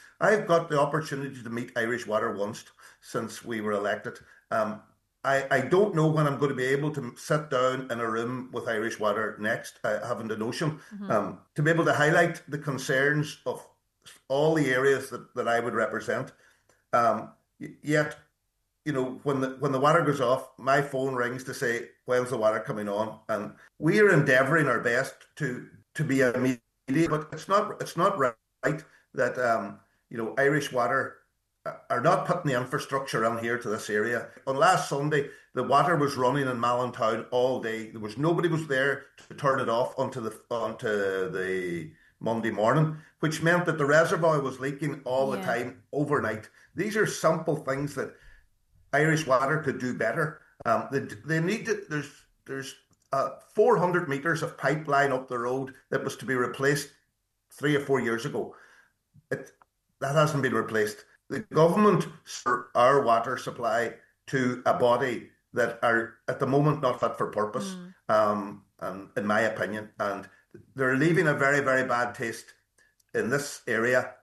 on this morning’s Nine til Noon Show